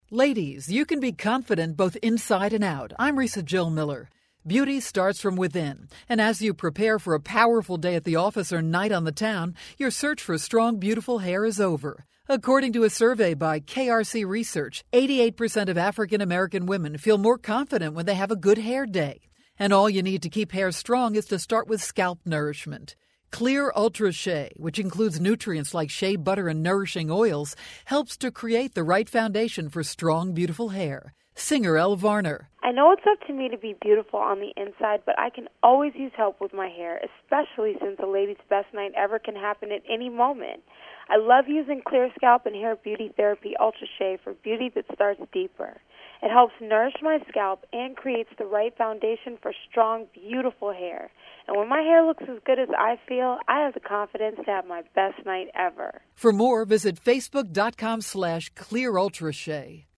October 26, 2012Posted in: Audio News Release